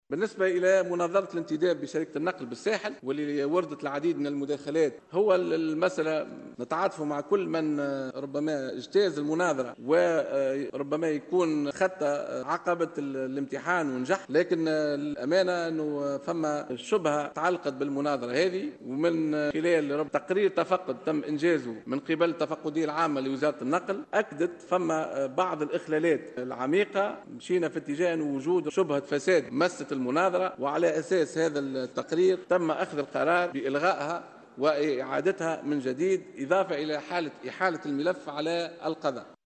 وأوضح خلال جلسة عامة بمجلس نواب الشعب لمناقشة ميزانية وزارة النقل، أن تقرير تفقّد قامت به التفقدية العامة لوزارة النقل أكد وجود اخلالات عميقة وشبهة فساد، مضيفا أنه سيتم إحالة الملف على القضاء.